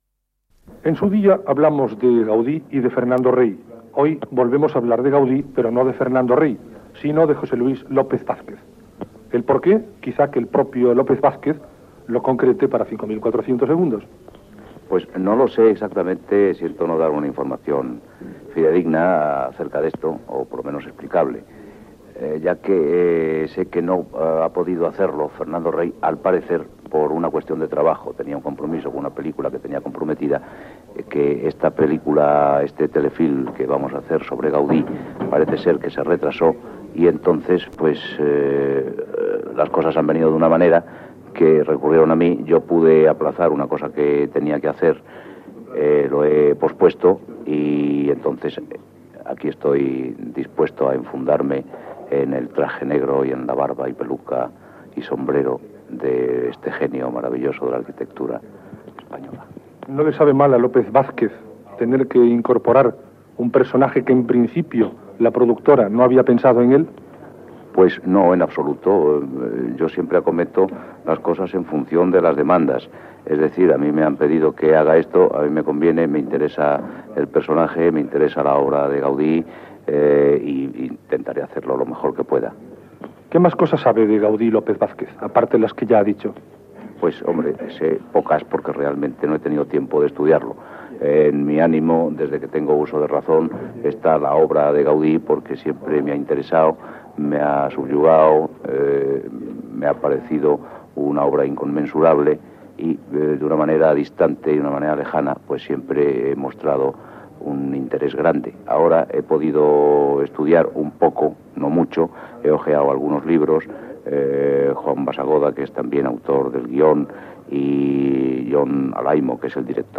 Entrevista a l'actor José Luis López Vázquez que protagonitzarà un telefilm dedicat a l'arquitecte Antoni Gaudí ("Antonio Gaudí, una visión inacabada")
Programa presentat per Tico Medina.